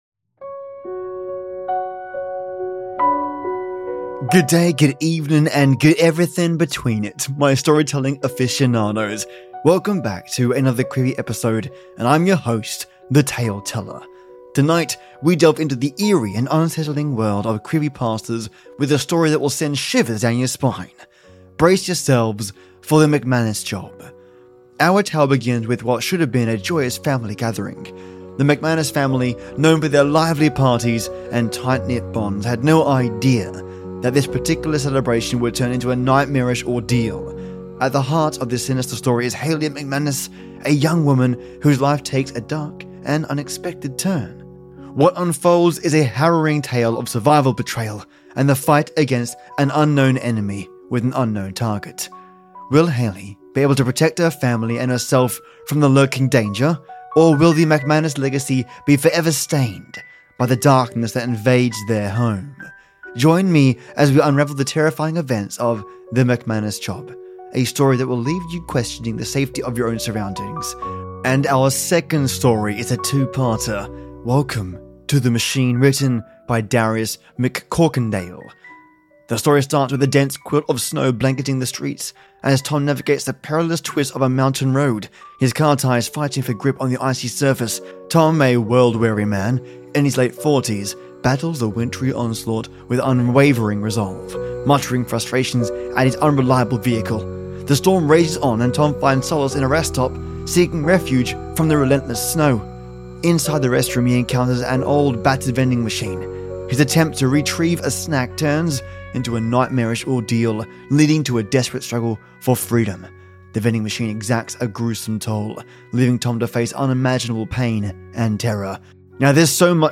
We're also starting another Creepypasta, "Welcome to the Machine." Due to my throat acting up, I recorded as much as I could amidst the rain, and I'll be finishing the story next week along with some previous folktales.